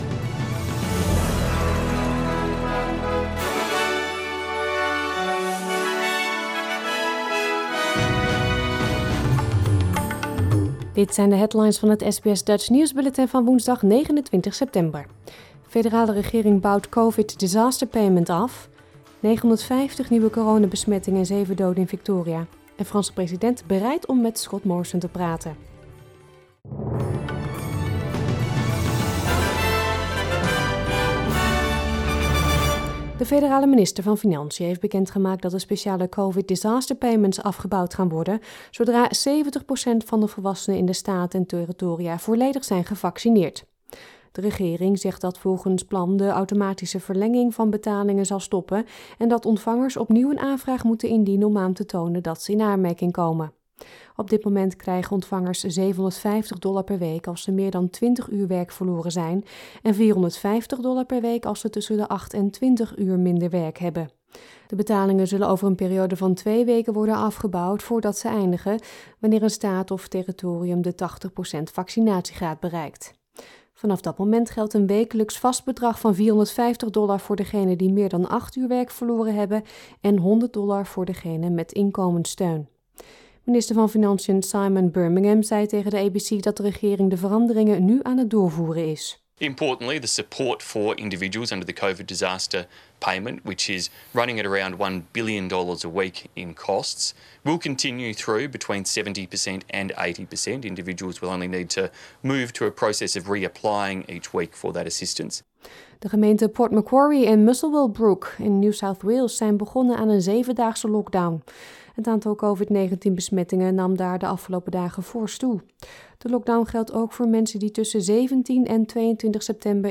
Nederlands/Australisch SBS Dutch nieuwsbulletin van woensdag 29 september 2021